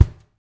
LG KICK2  -L.wav